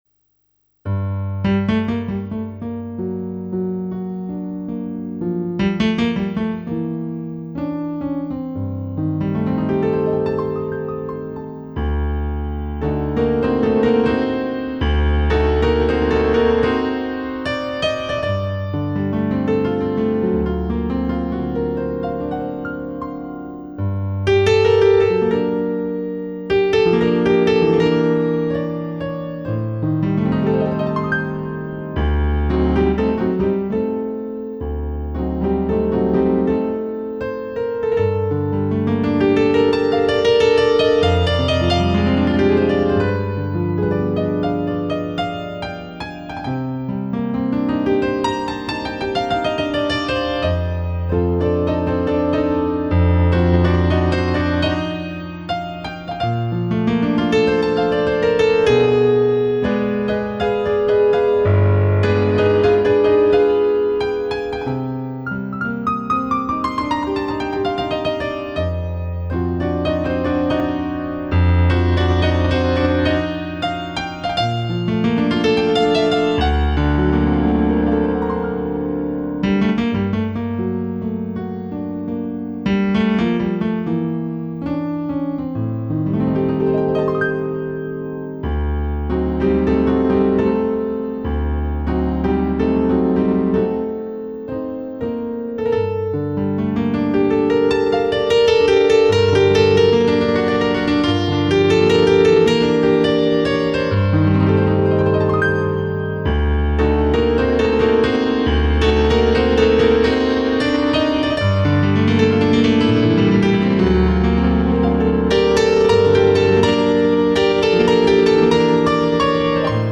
мелодия на фортепиано (или пианино)